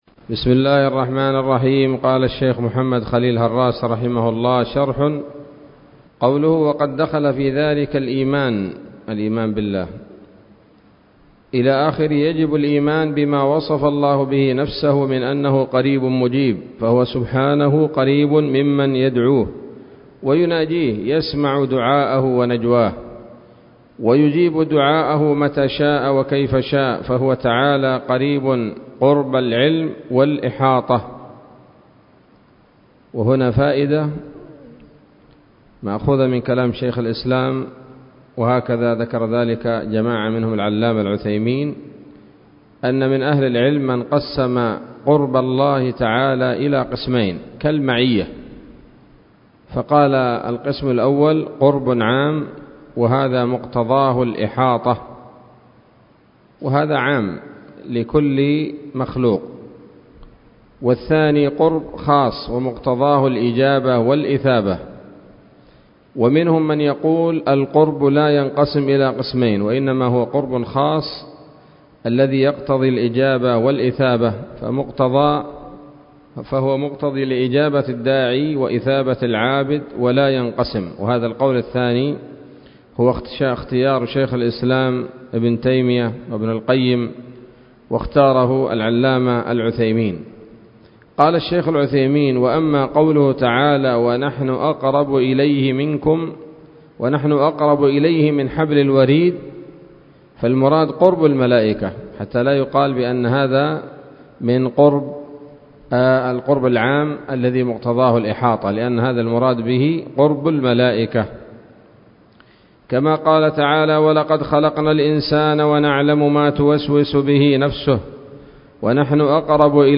الدرس الثالث والتسعون من شرح العقيدة الواسطية للهراس